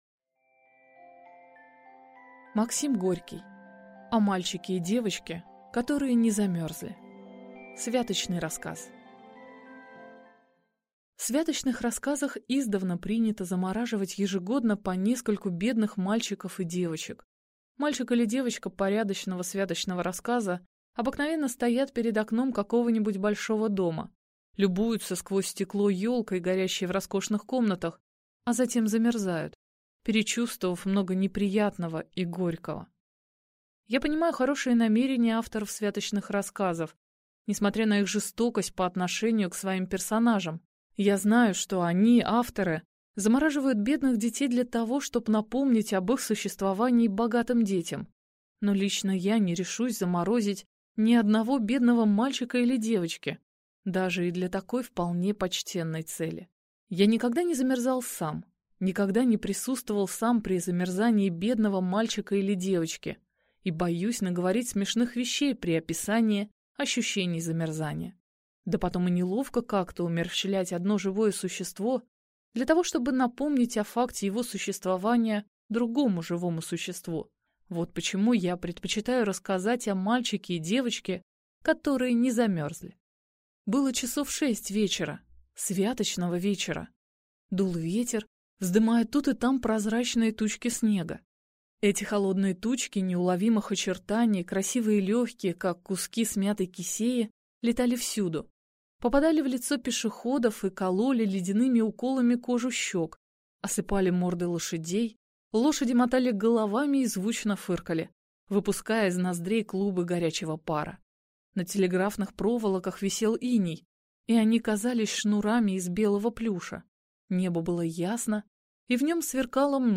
Аудиокнига О мальчике и девочке, которые не замёрзли | Библиотека аудиокниг